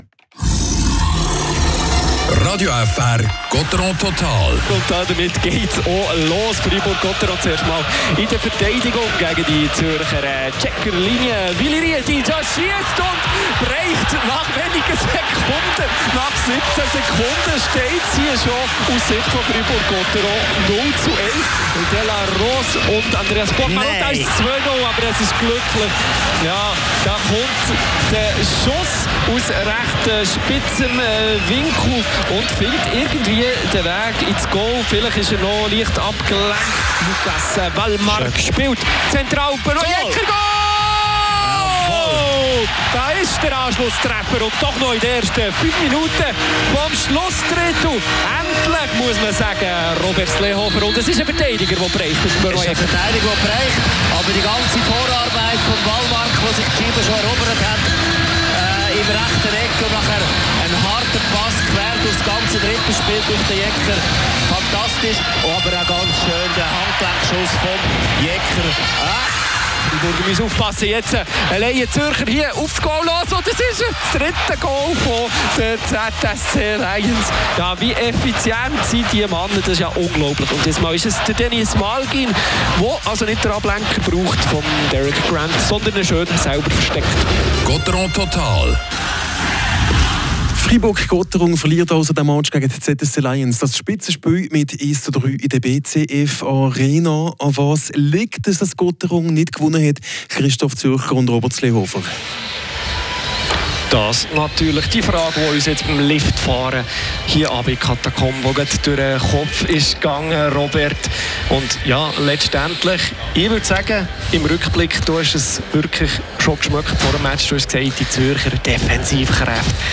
Interview mit dem